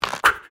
bow_draw-n-shoot-04.mp3